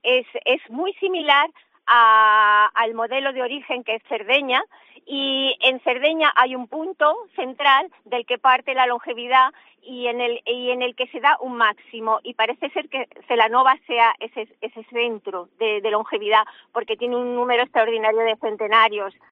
En una entrevista en Cope Ourense